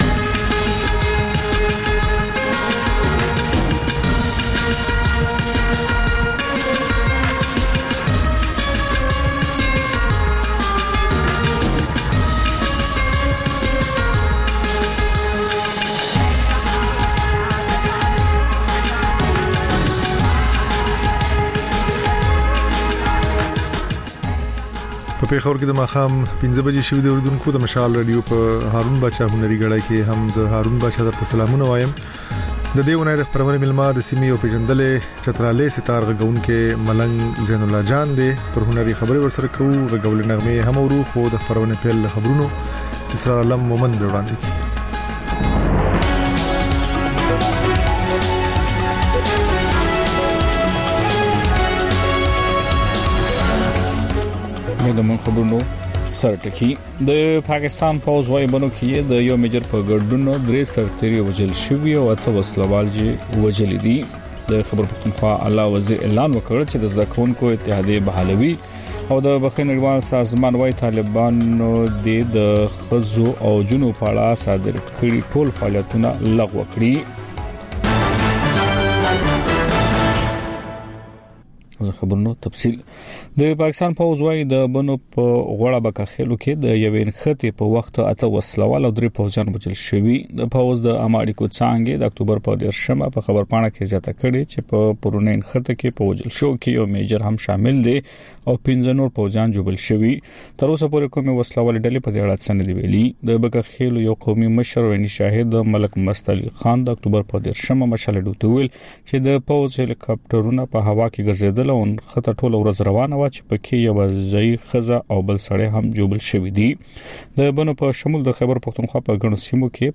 د خپرونې پیل له خبرونو کېږي، بیا ورپسې رپورټونه خپرېږي. ورسره یوه اوونیزه خپرونه درخپروو. ځینې ورځې دا ماښامنۍ خپرونه مو یوې ژوندۍ اوونیزې خپرونې ته ځانګړې کړې وي چې تر خبرونو سمدستي وروسته خپرېږي.